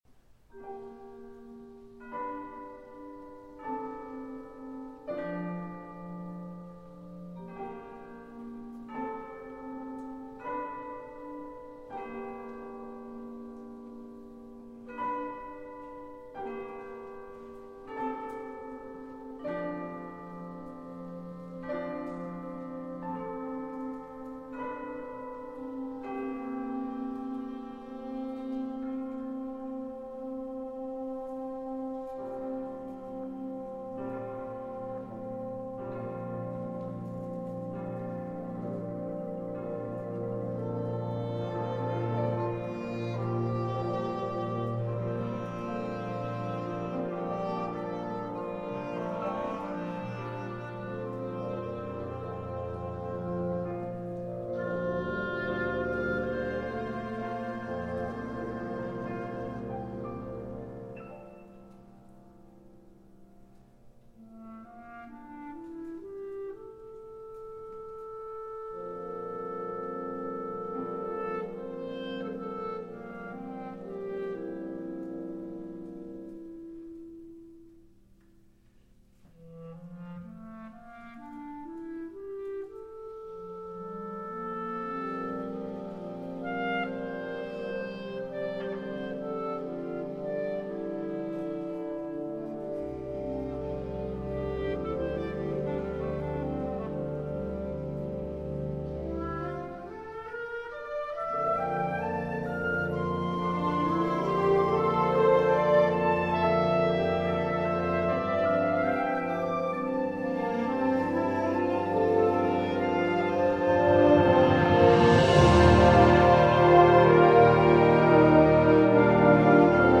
Genre: Band
Piano
Timpani (4 drums)